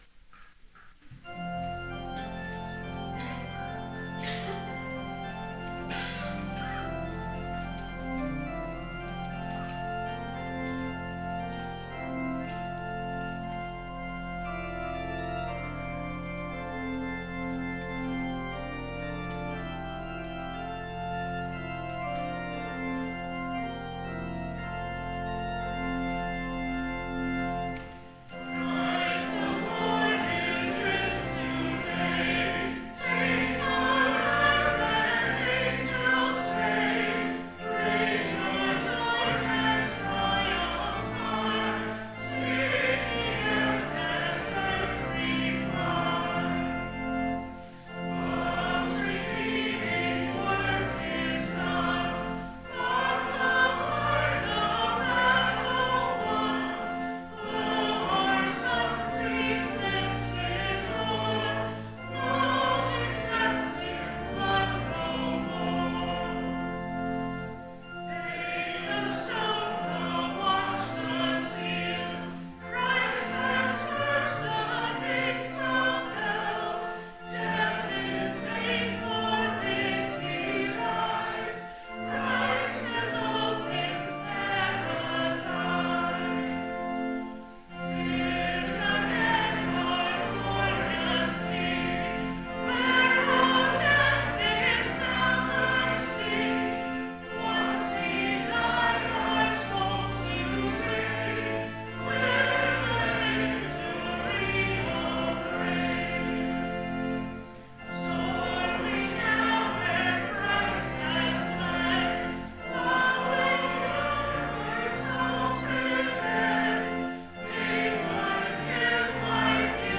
Biblical Text: Acts 5:28-42 Full Sermon Draft